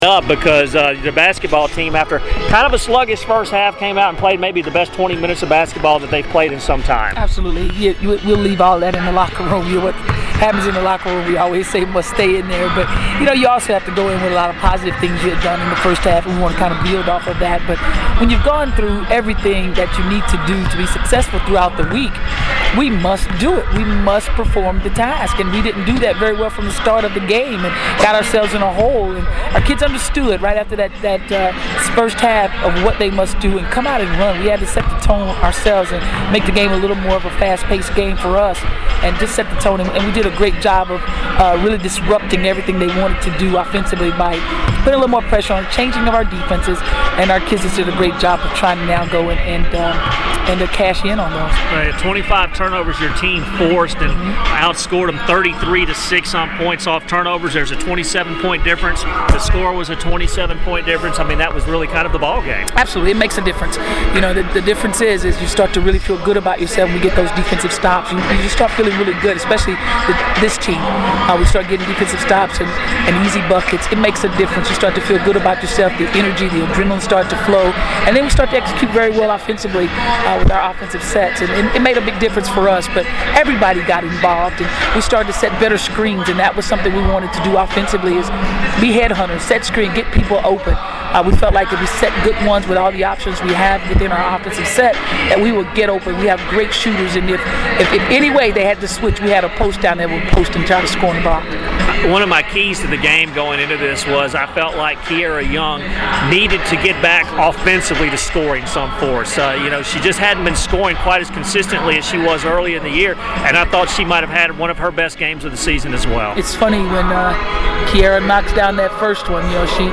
Weatherspoon Postgame Audio